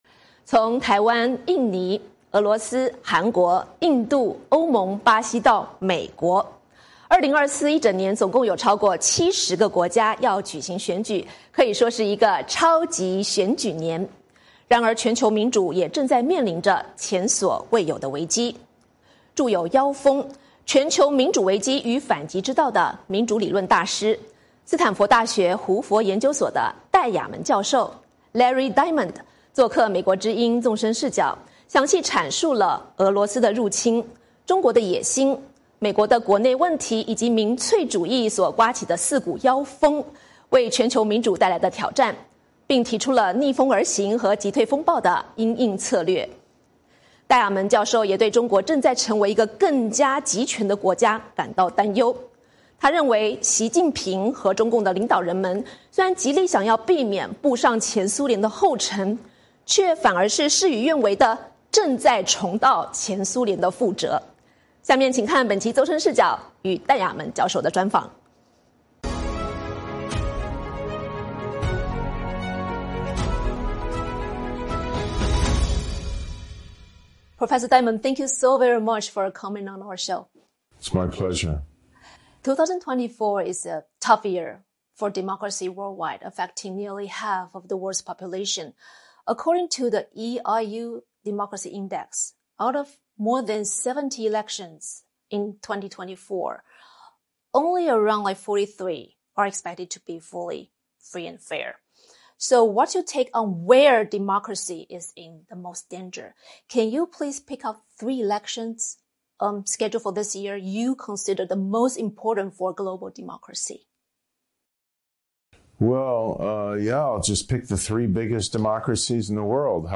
专访戴雅门：中共能否和平转型？